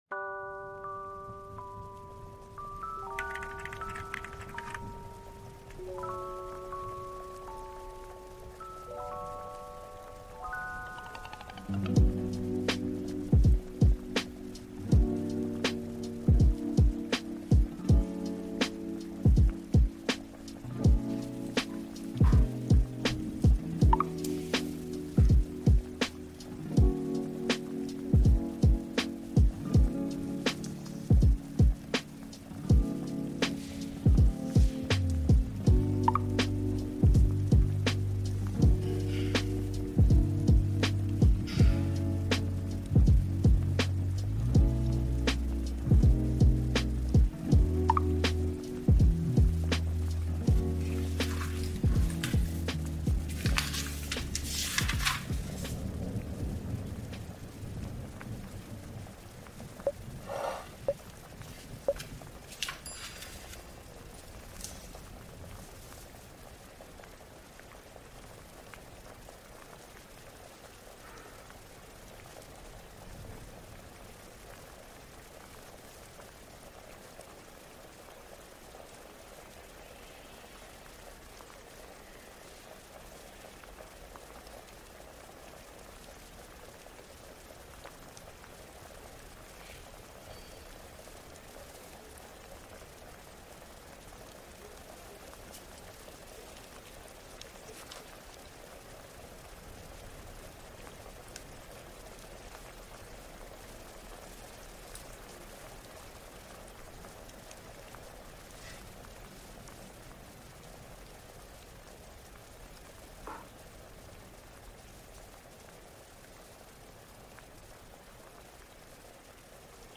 Connexion Lumineuse : 441 Hz Clarté